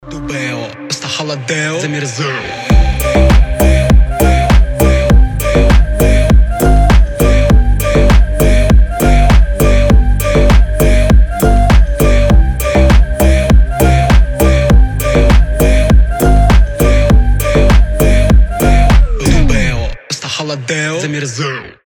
deep house
атмосферные
качающие